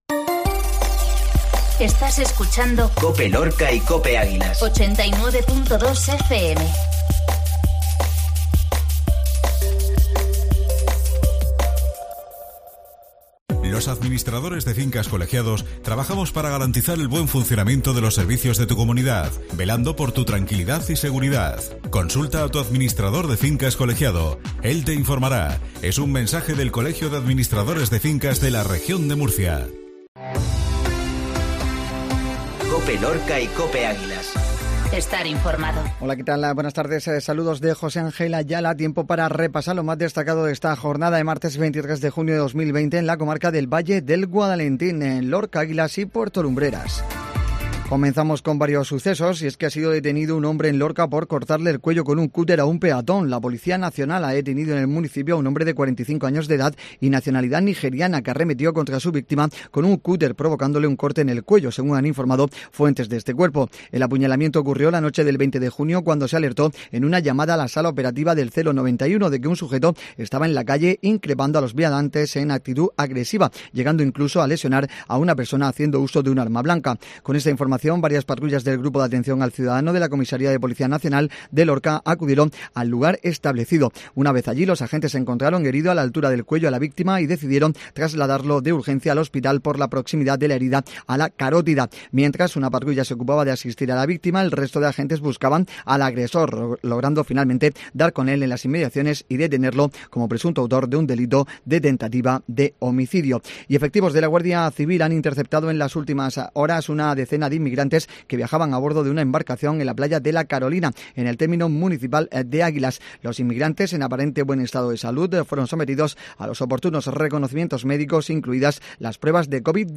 INFORMATIVO MEDIODÍA MARTES